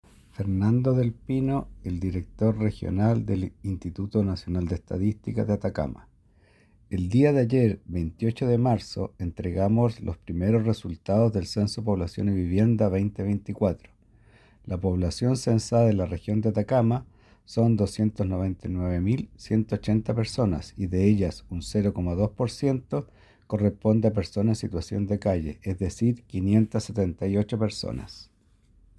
Director-INE-Personas-situacion-Calle.mp3